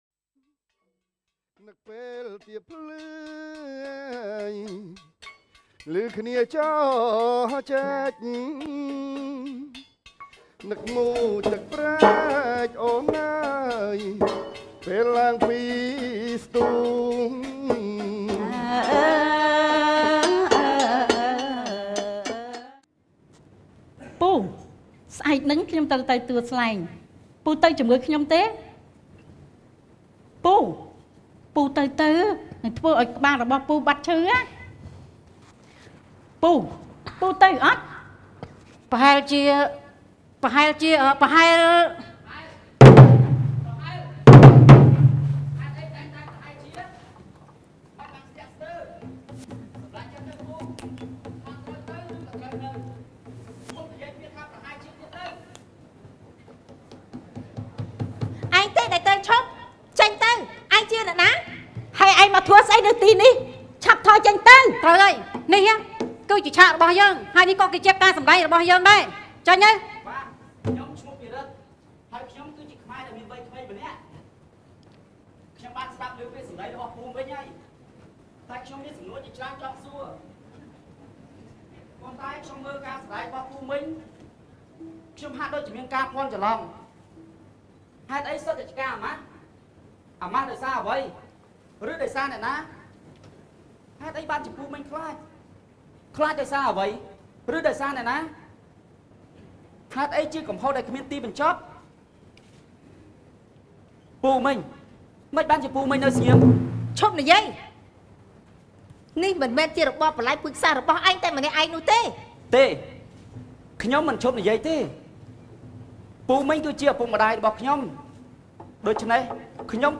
Scene 8: A Discussion Between Two Generations
A New Cambodian Play: Breaking The Silence